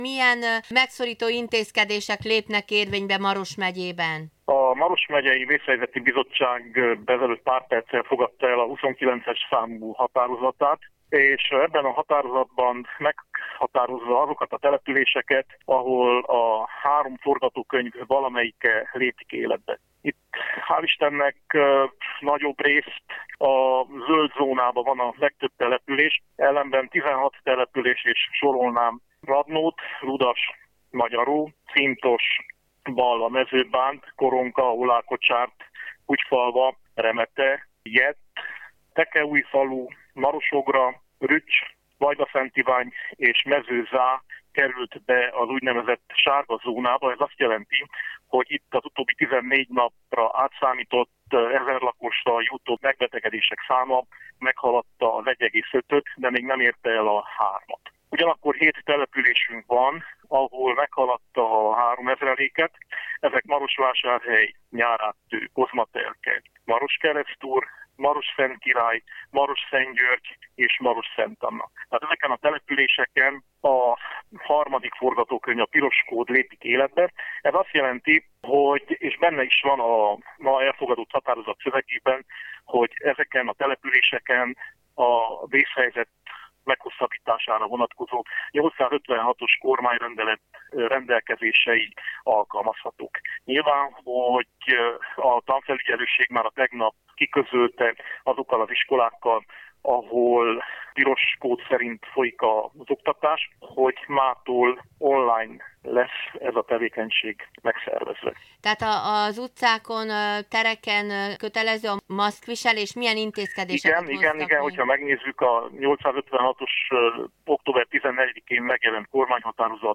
Nagy Zsigmond Maros megyei alprefektus rádiónknak beszámol arról is, hogy melyek azok a települések, amelyek sárga zónába esnek.